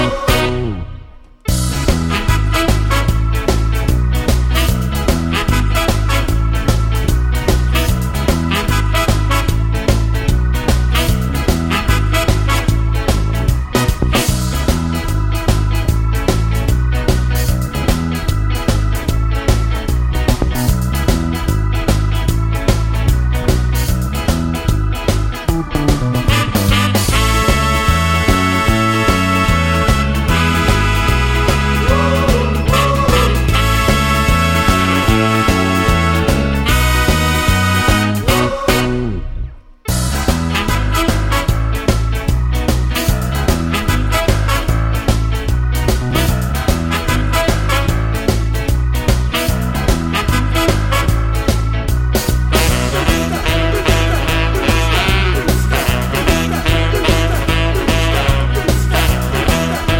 no harmonica Ska 2:37 Buy £1.50